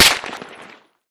light_crack_07.ogg